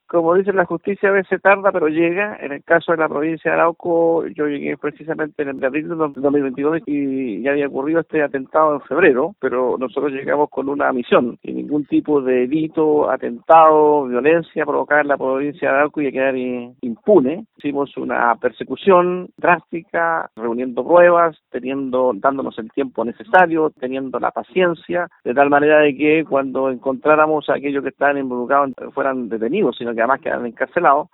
El delegado presidencial de la Provincia de Arauco, Humberto Toro, dijo que se han reunido las pruebas suficientes. Luego de estar 4 años en libertad, los imputados enfrentarán a la justicia.